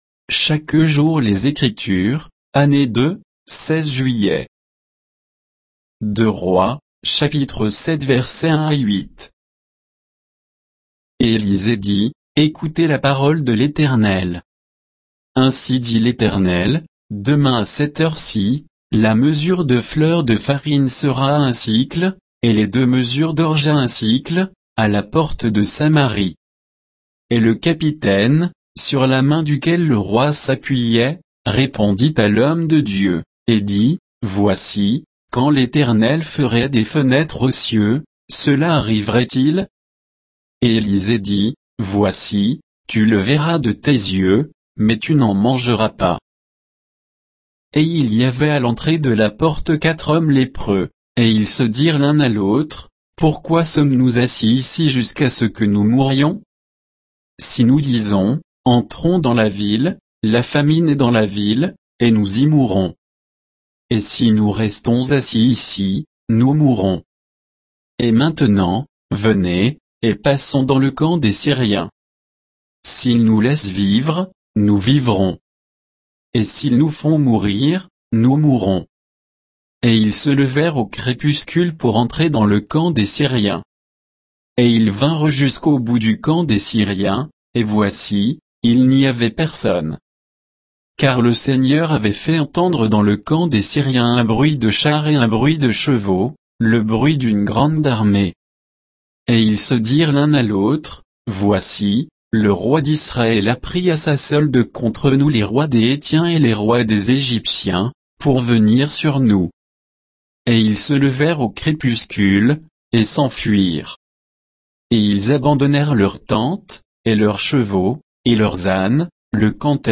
Méditation quoditienne de Chaque jour les Écritures sur 2 Rois 7, 1 à 8